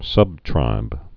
(sŭbtrīb)